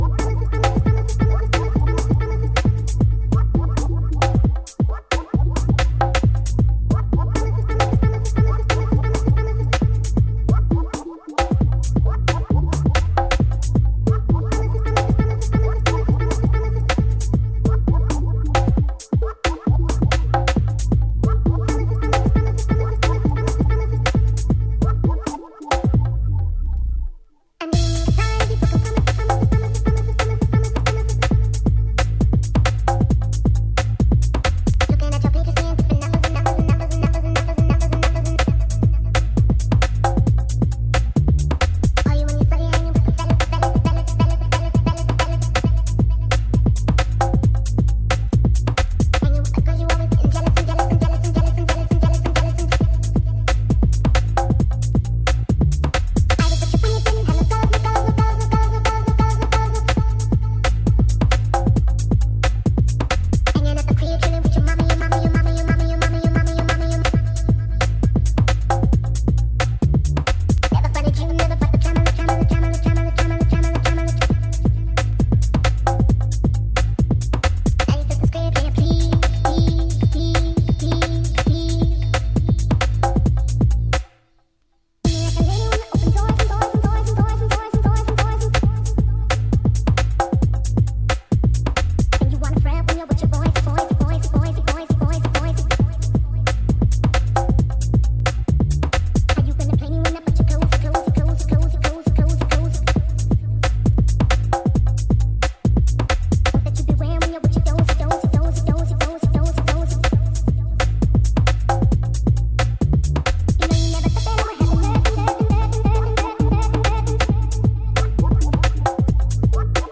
オートチューン風のタイムストレッチヴォーカルが幻想的にこだまする
ディープでファットな低音が気持ち良い、バッチリ世界水準のフロアチューンが揃う傑作。